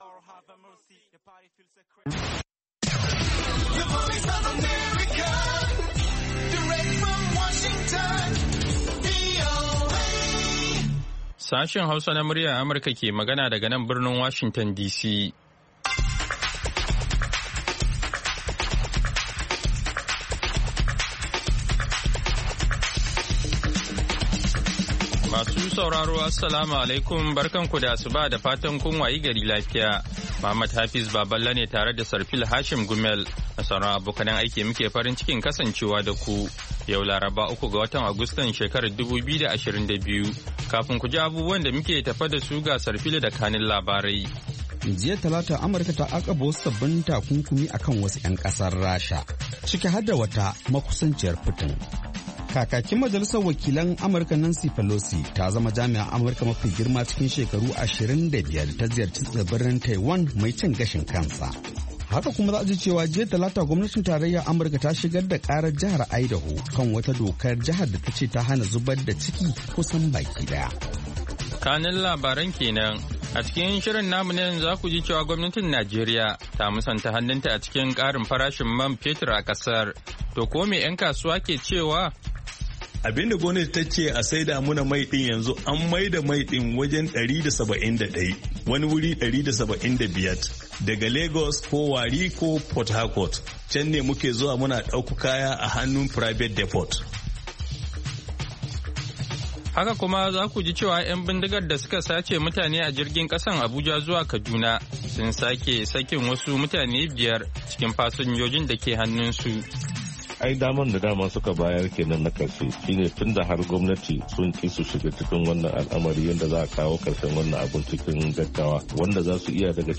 Kullum da karfe 6 na safe agogon Najeriya da Nijar muna gabatar da labarai da rahotanni da dumi-duminsu, sannan mu na gabatar da wasu shirye-shirye kamar Noma da Lafiya Uwar Jiki.